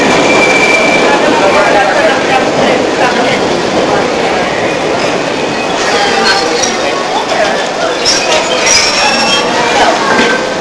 描述：录音：伦敦地铁（The Tube）的录音。2006年9月间录制的。这是列车在车站前刹车的情况。
Tag: 到达 制动 现场记录 伦敦 地铁 车站 列车 地下